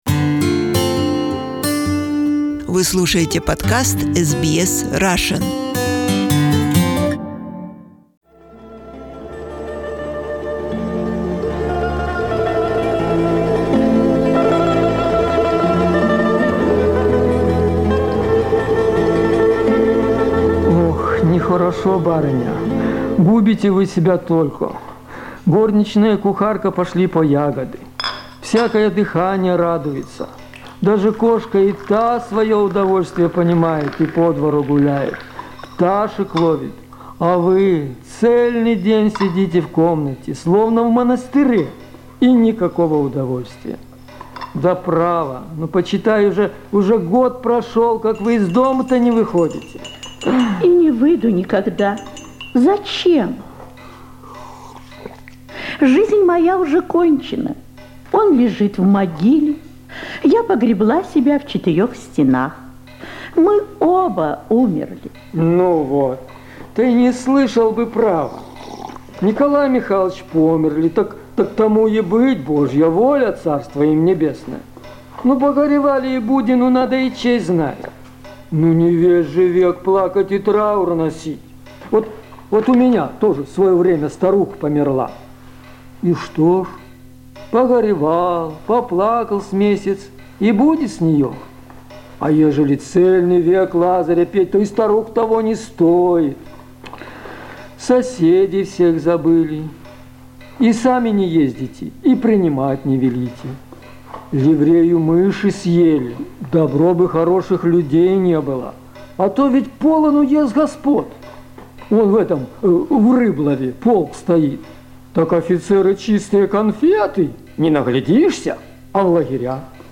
The Bear. A comedy in one Act. Radio Play
His energy attracted to the studio many amateur and professional actors of different ages from many different cities of the former USSR.